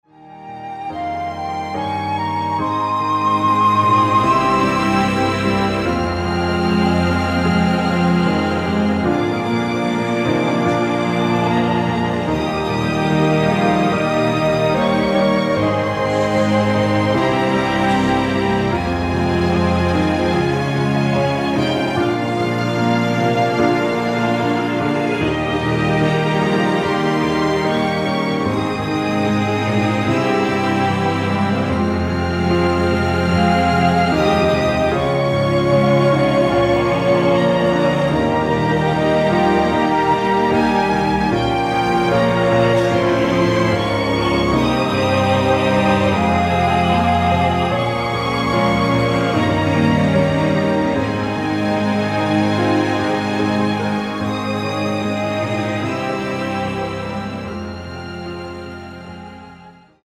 원키에서(-1)내린 코러스 포함된 MR입니다.(미리듣기 확인)
Ab
앞부분30초, 뒷부분30초씩 편집해서 올려 드리고 있습니다.